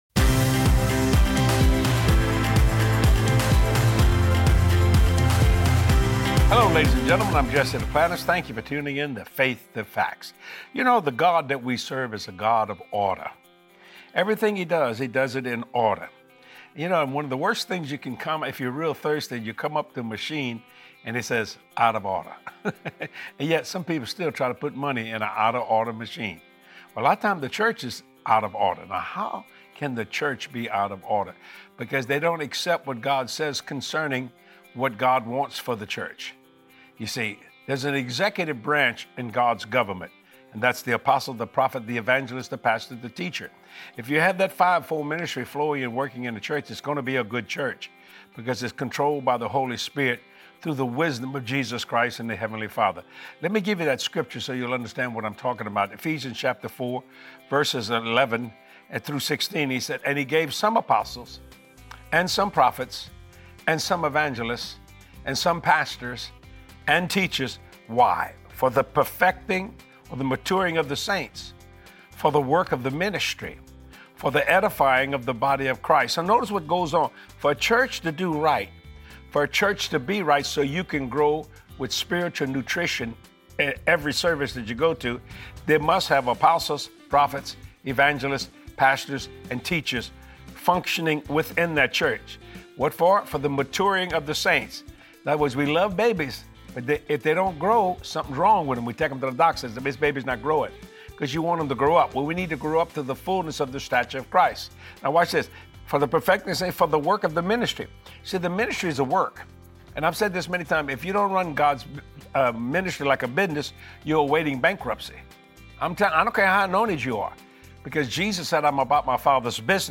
God is a God of ORDER. Watch this faith-filled teaching with Jesse and be empowered to grow where the Lord wants you planted.